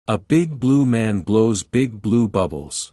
Tongue Twisters!